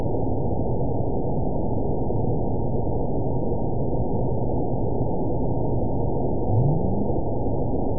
event 911406 date 02/25/22 time 03:55:45 GMT (3 years, 8 months ago) score 8.78 location TSS-AB02 detected by nrw target species NRW annotations +NRW Spectrogram: Frequency (kHz) vs. Time (s) audio not available .wav